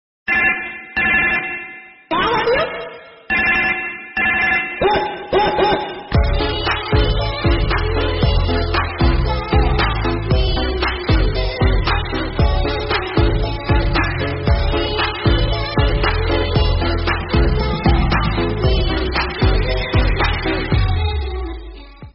Kategori: Nada dering
Ini adalah nada dering TikTok yang sedang populer.